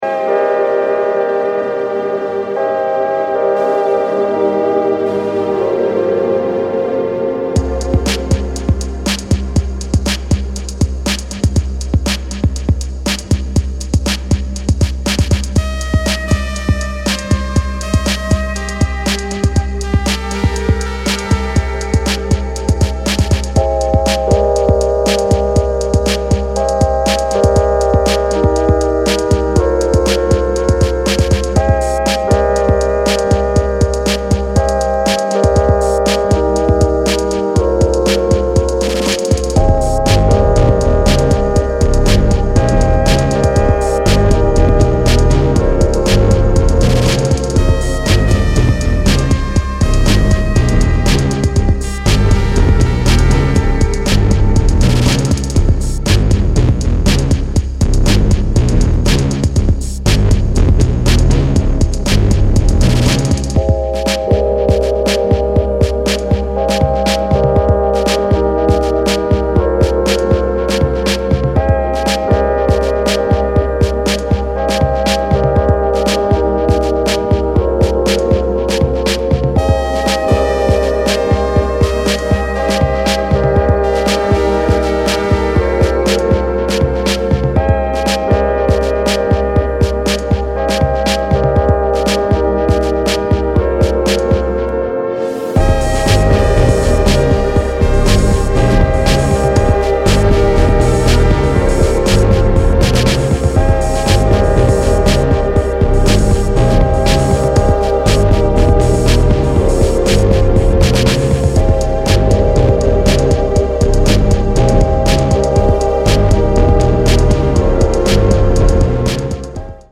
Intuitive analog Electronix